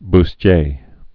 (bs-tyā, bŭs-)